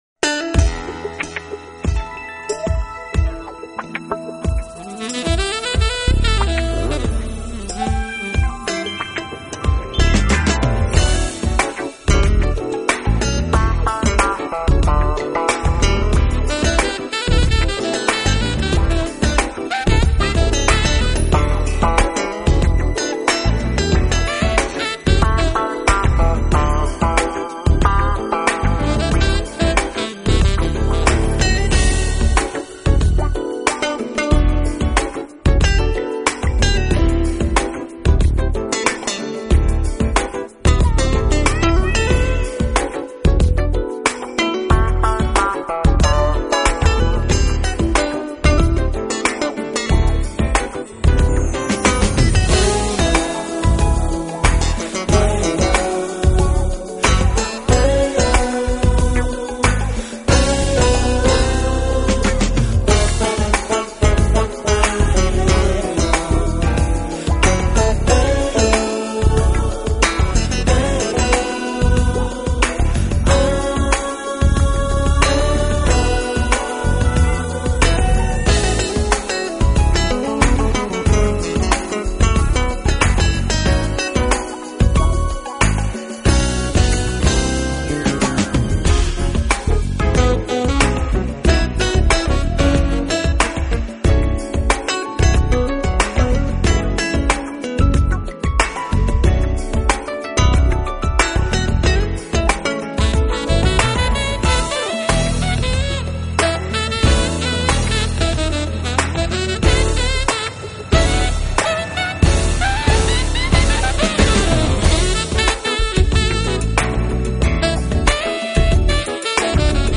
rock, jazz and R&B